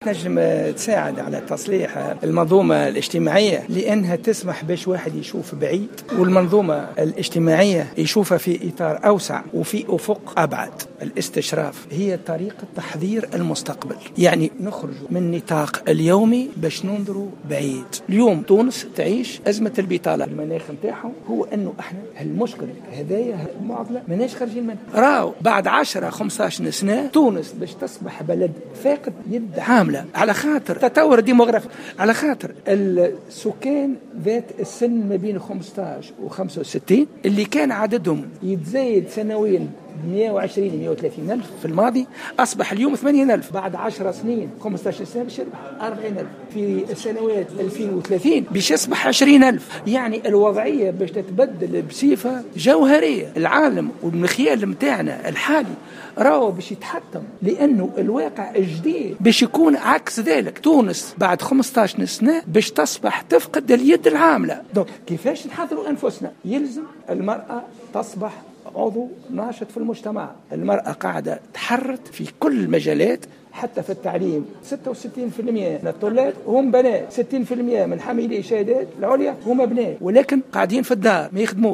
Le ministre des affaires sociales, Mahmoud Ben Romdhane a déclaré ce jeudi 3 mars 2016 dans une intervention sur les ondes de Jawhara FM, que la Tunisie n’aura plus, dans 15 ans, de main d’œuvre.